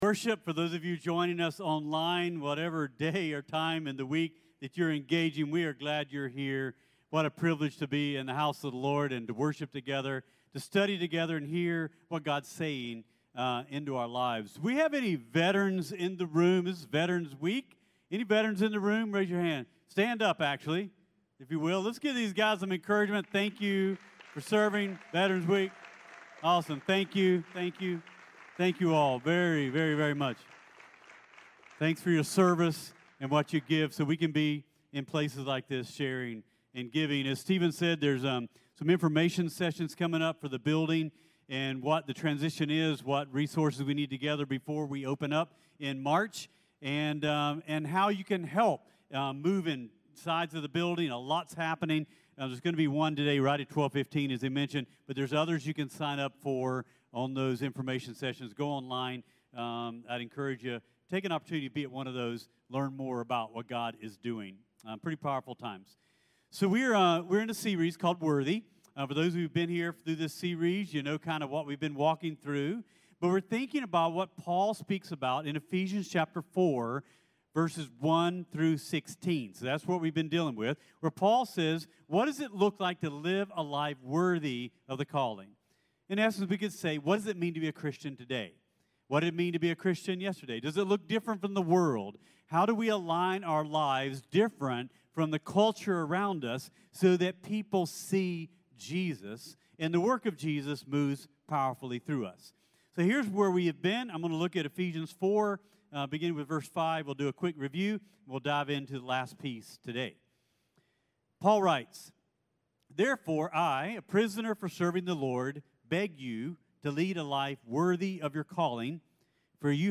CTK-Clipped-Full-Sermon-.mp3